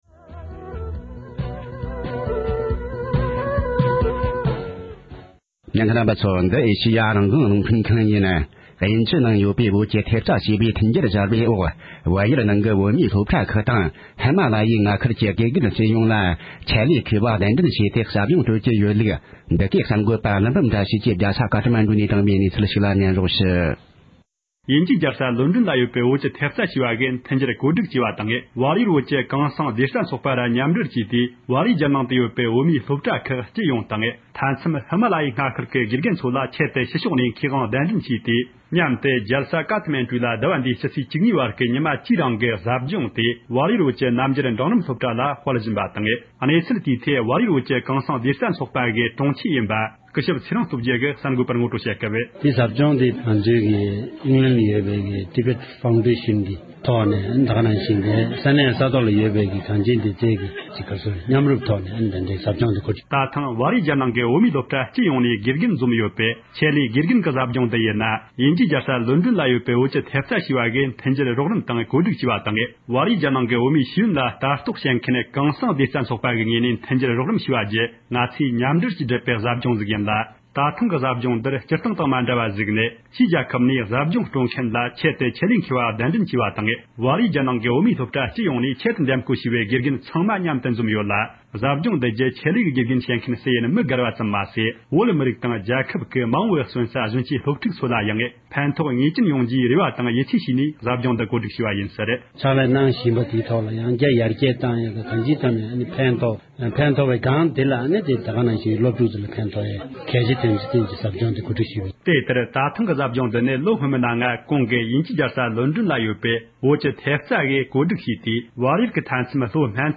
བལ་ཡུལ་ནས་བཏང་འབྱོར་བྱུང་བའི་གནས་ཚུལ་ཞིག་ལ་གསན་རོགས༎